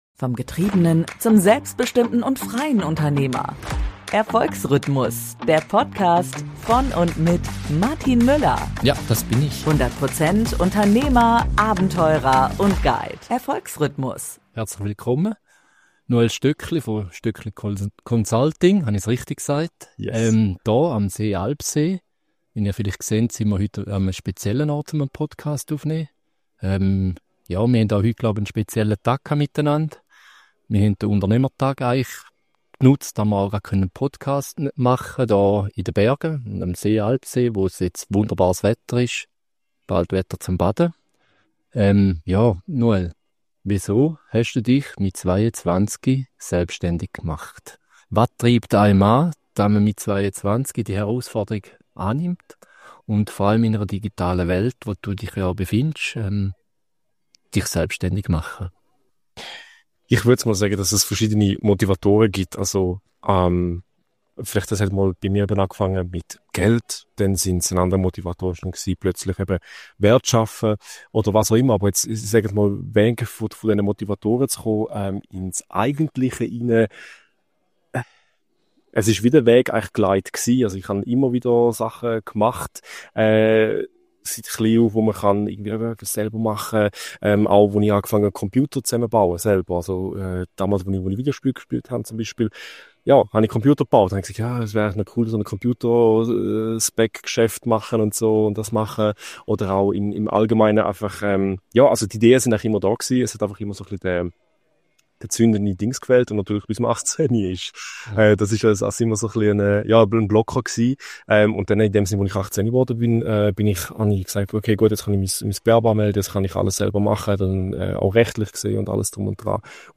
Ein Gespräch über Unternehmertum, Selbstreflexion und den Mut, sich für das Wesentliche zu entscheiden.